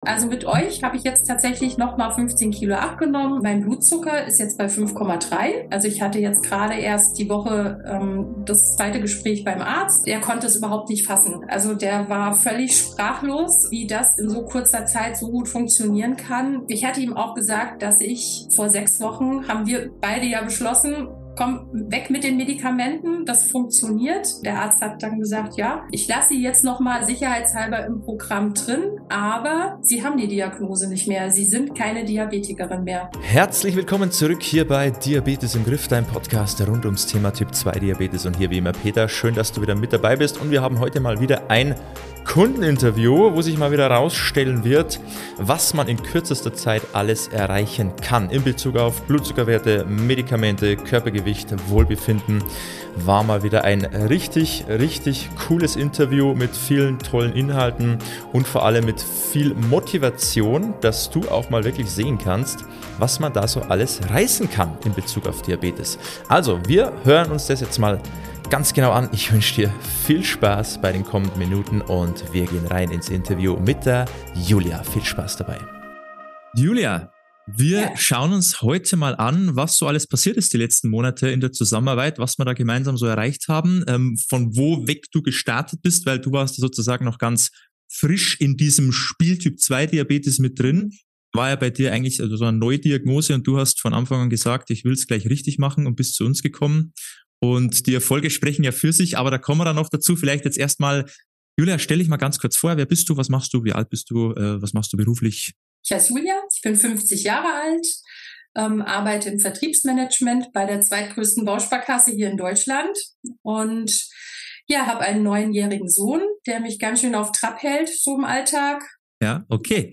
#197 Blutzucker verbessert ohne Medikamente - Kein Diabetes mehr? Kundeninterview